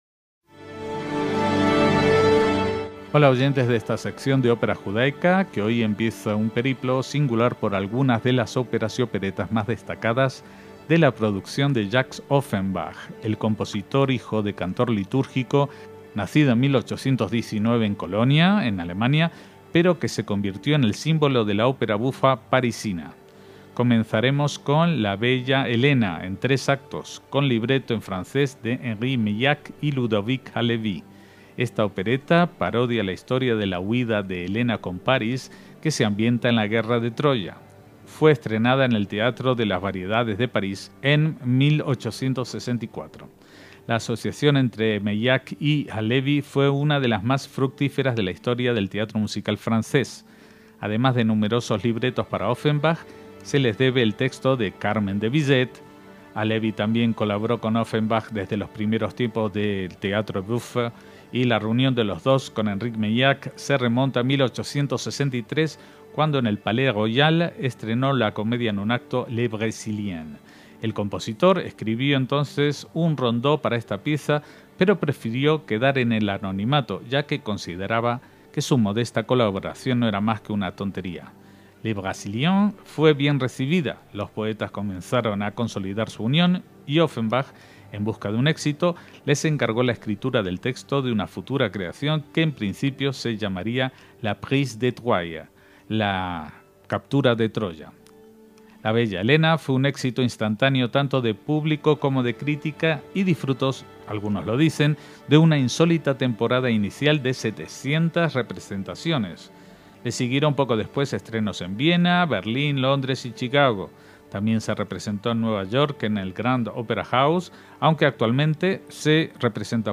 es una ópera bufa en tres actos